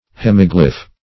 Search Result for " hemiglyph" : The Collaborative International Dictionary of English v.0.48: Hemiglyph \Hem"i*glyph\, n. [Hemi- + Gr.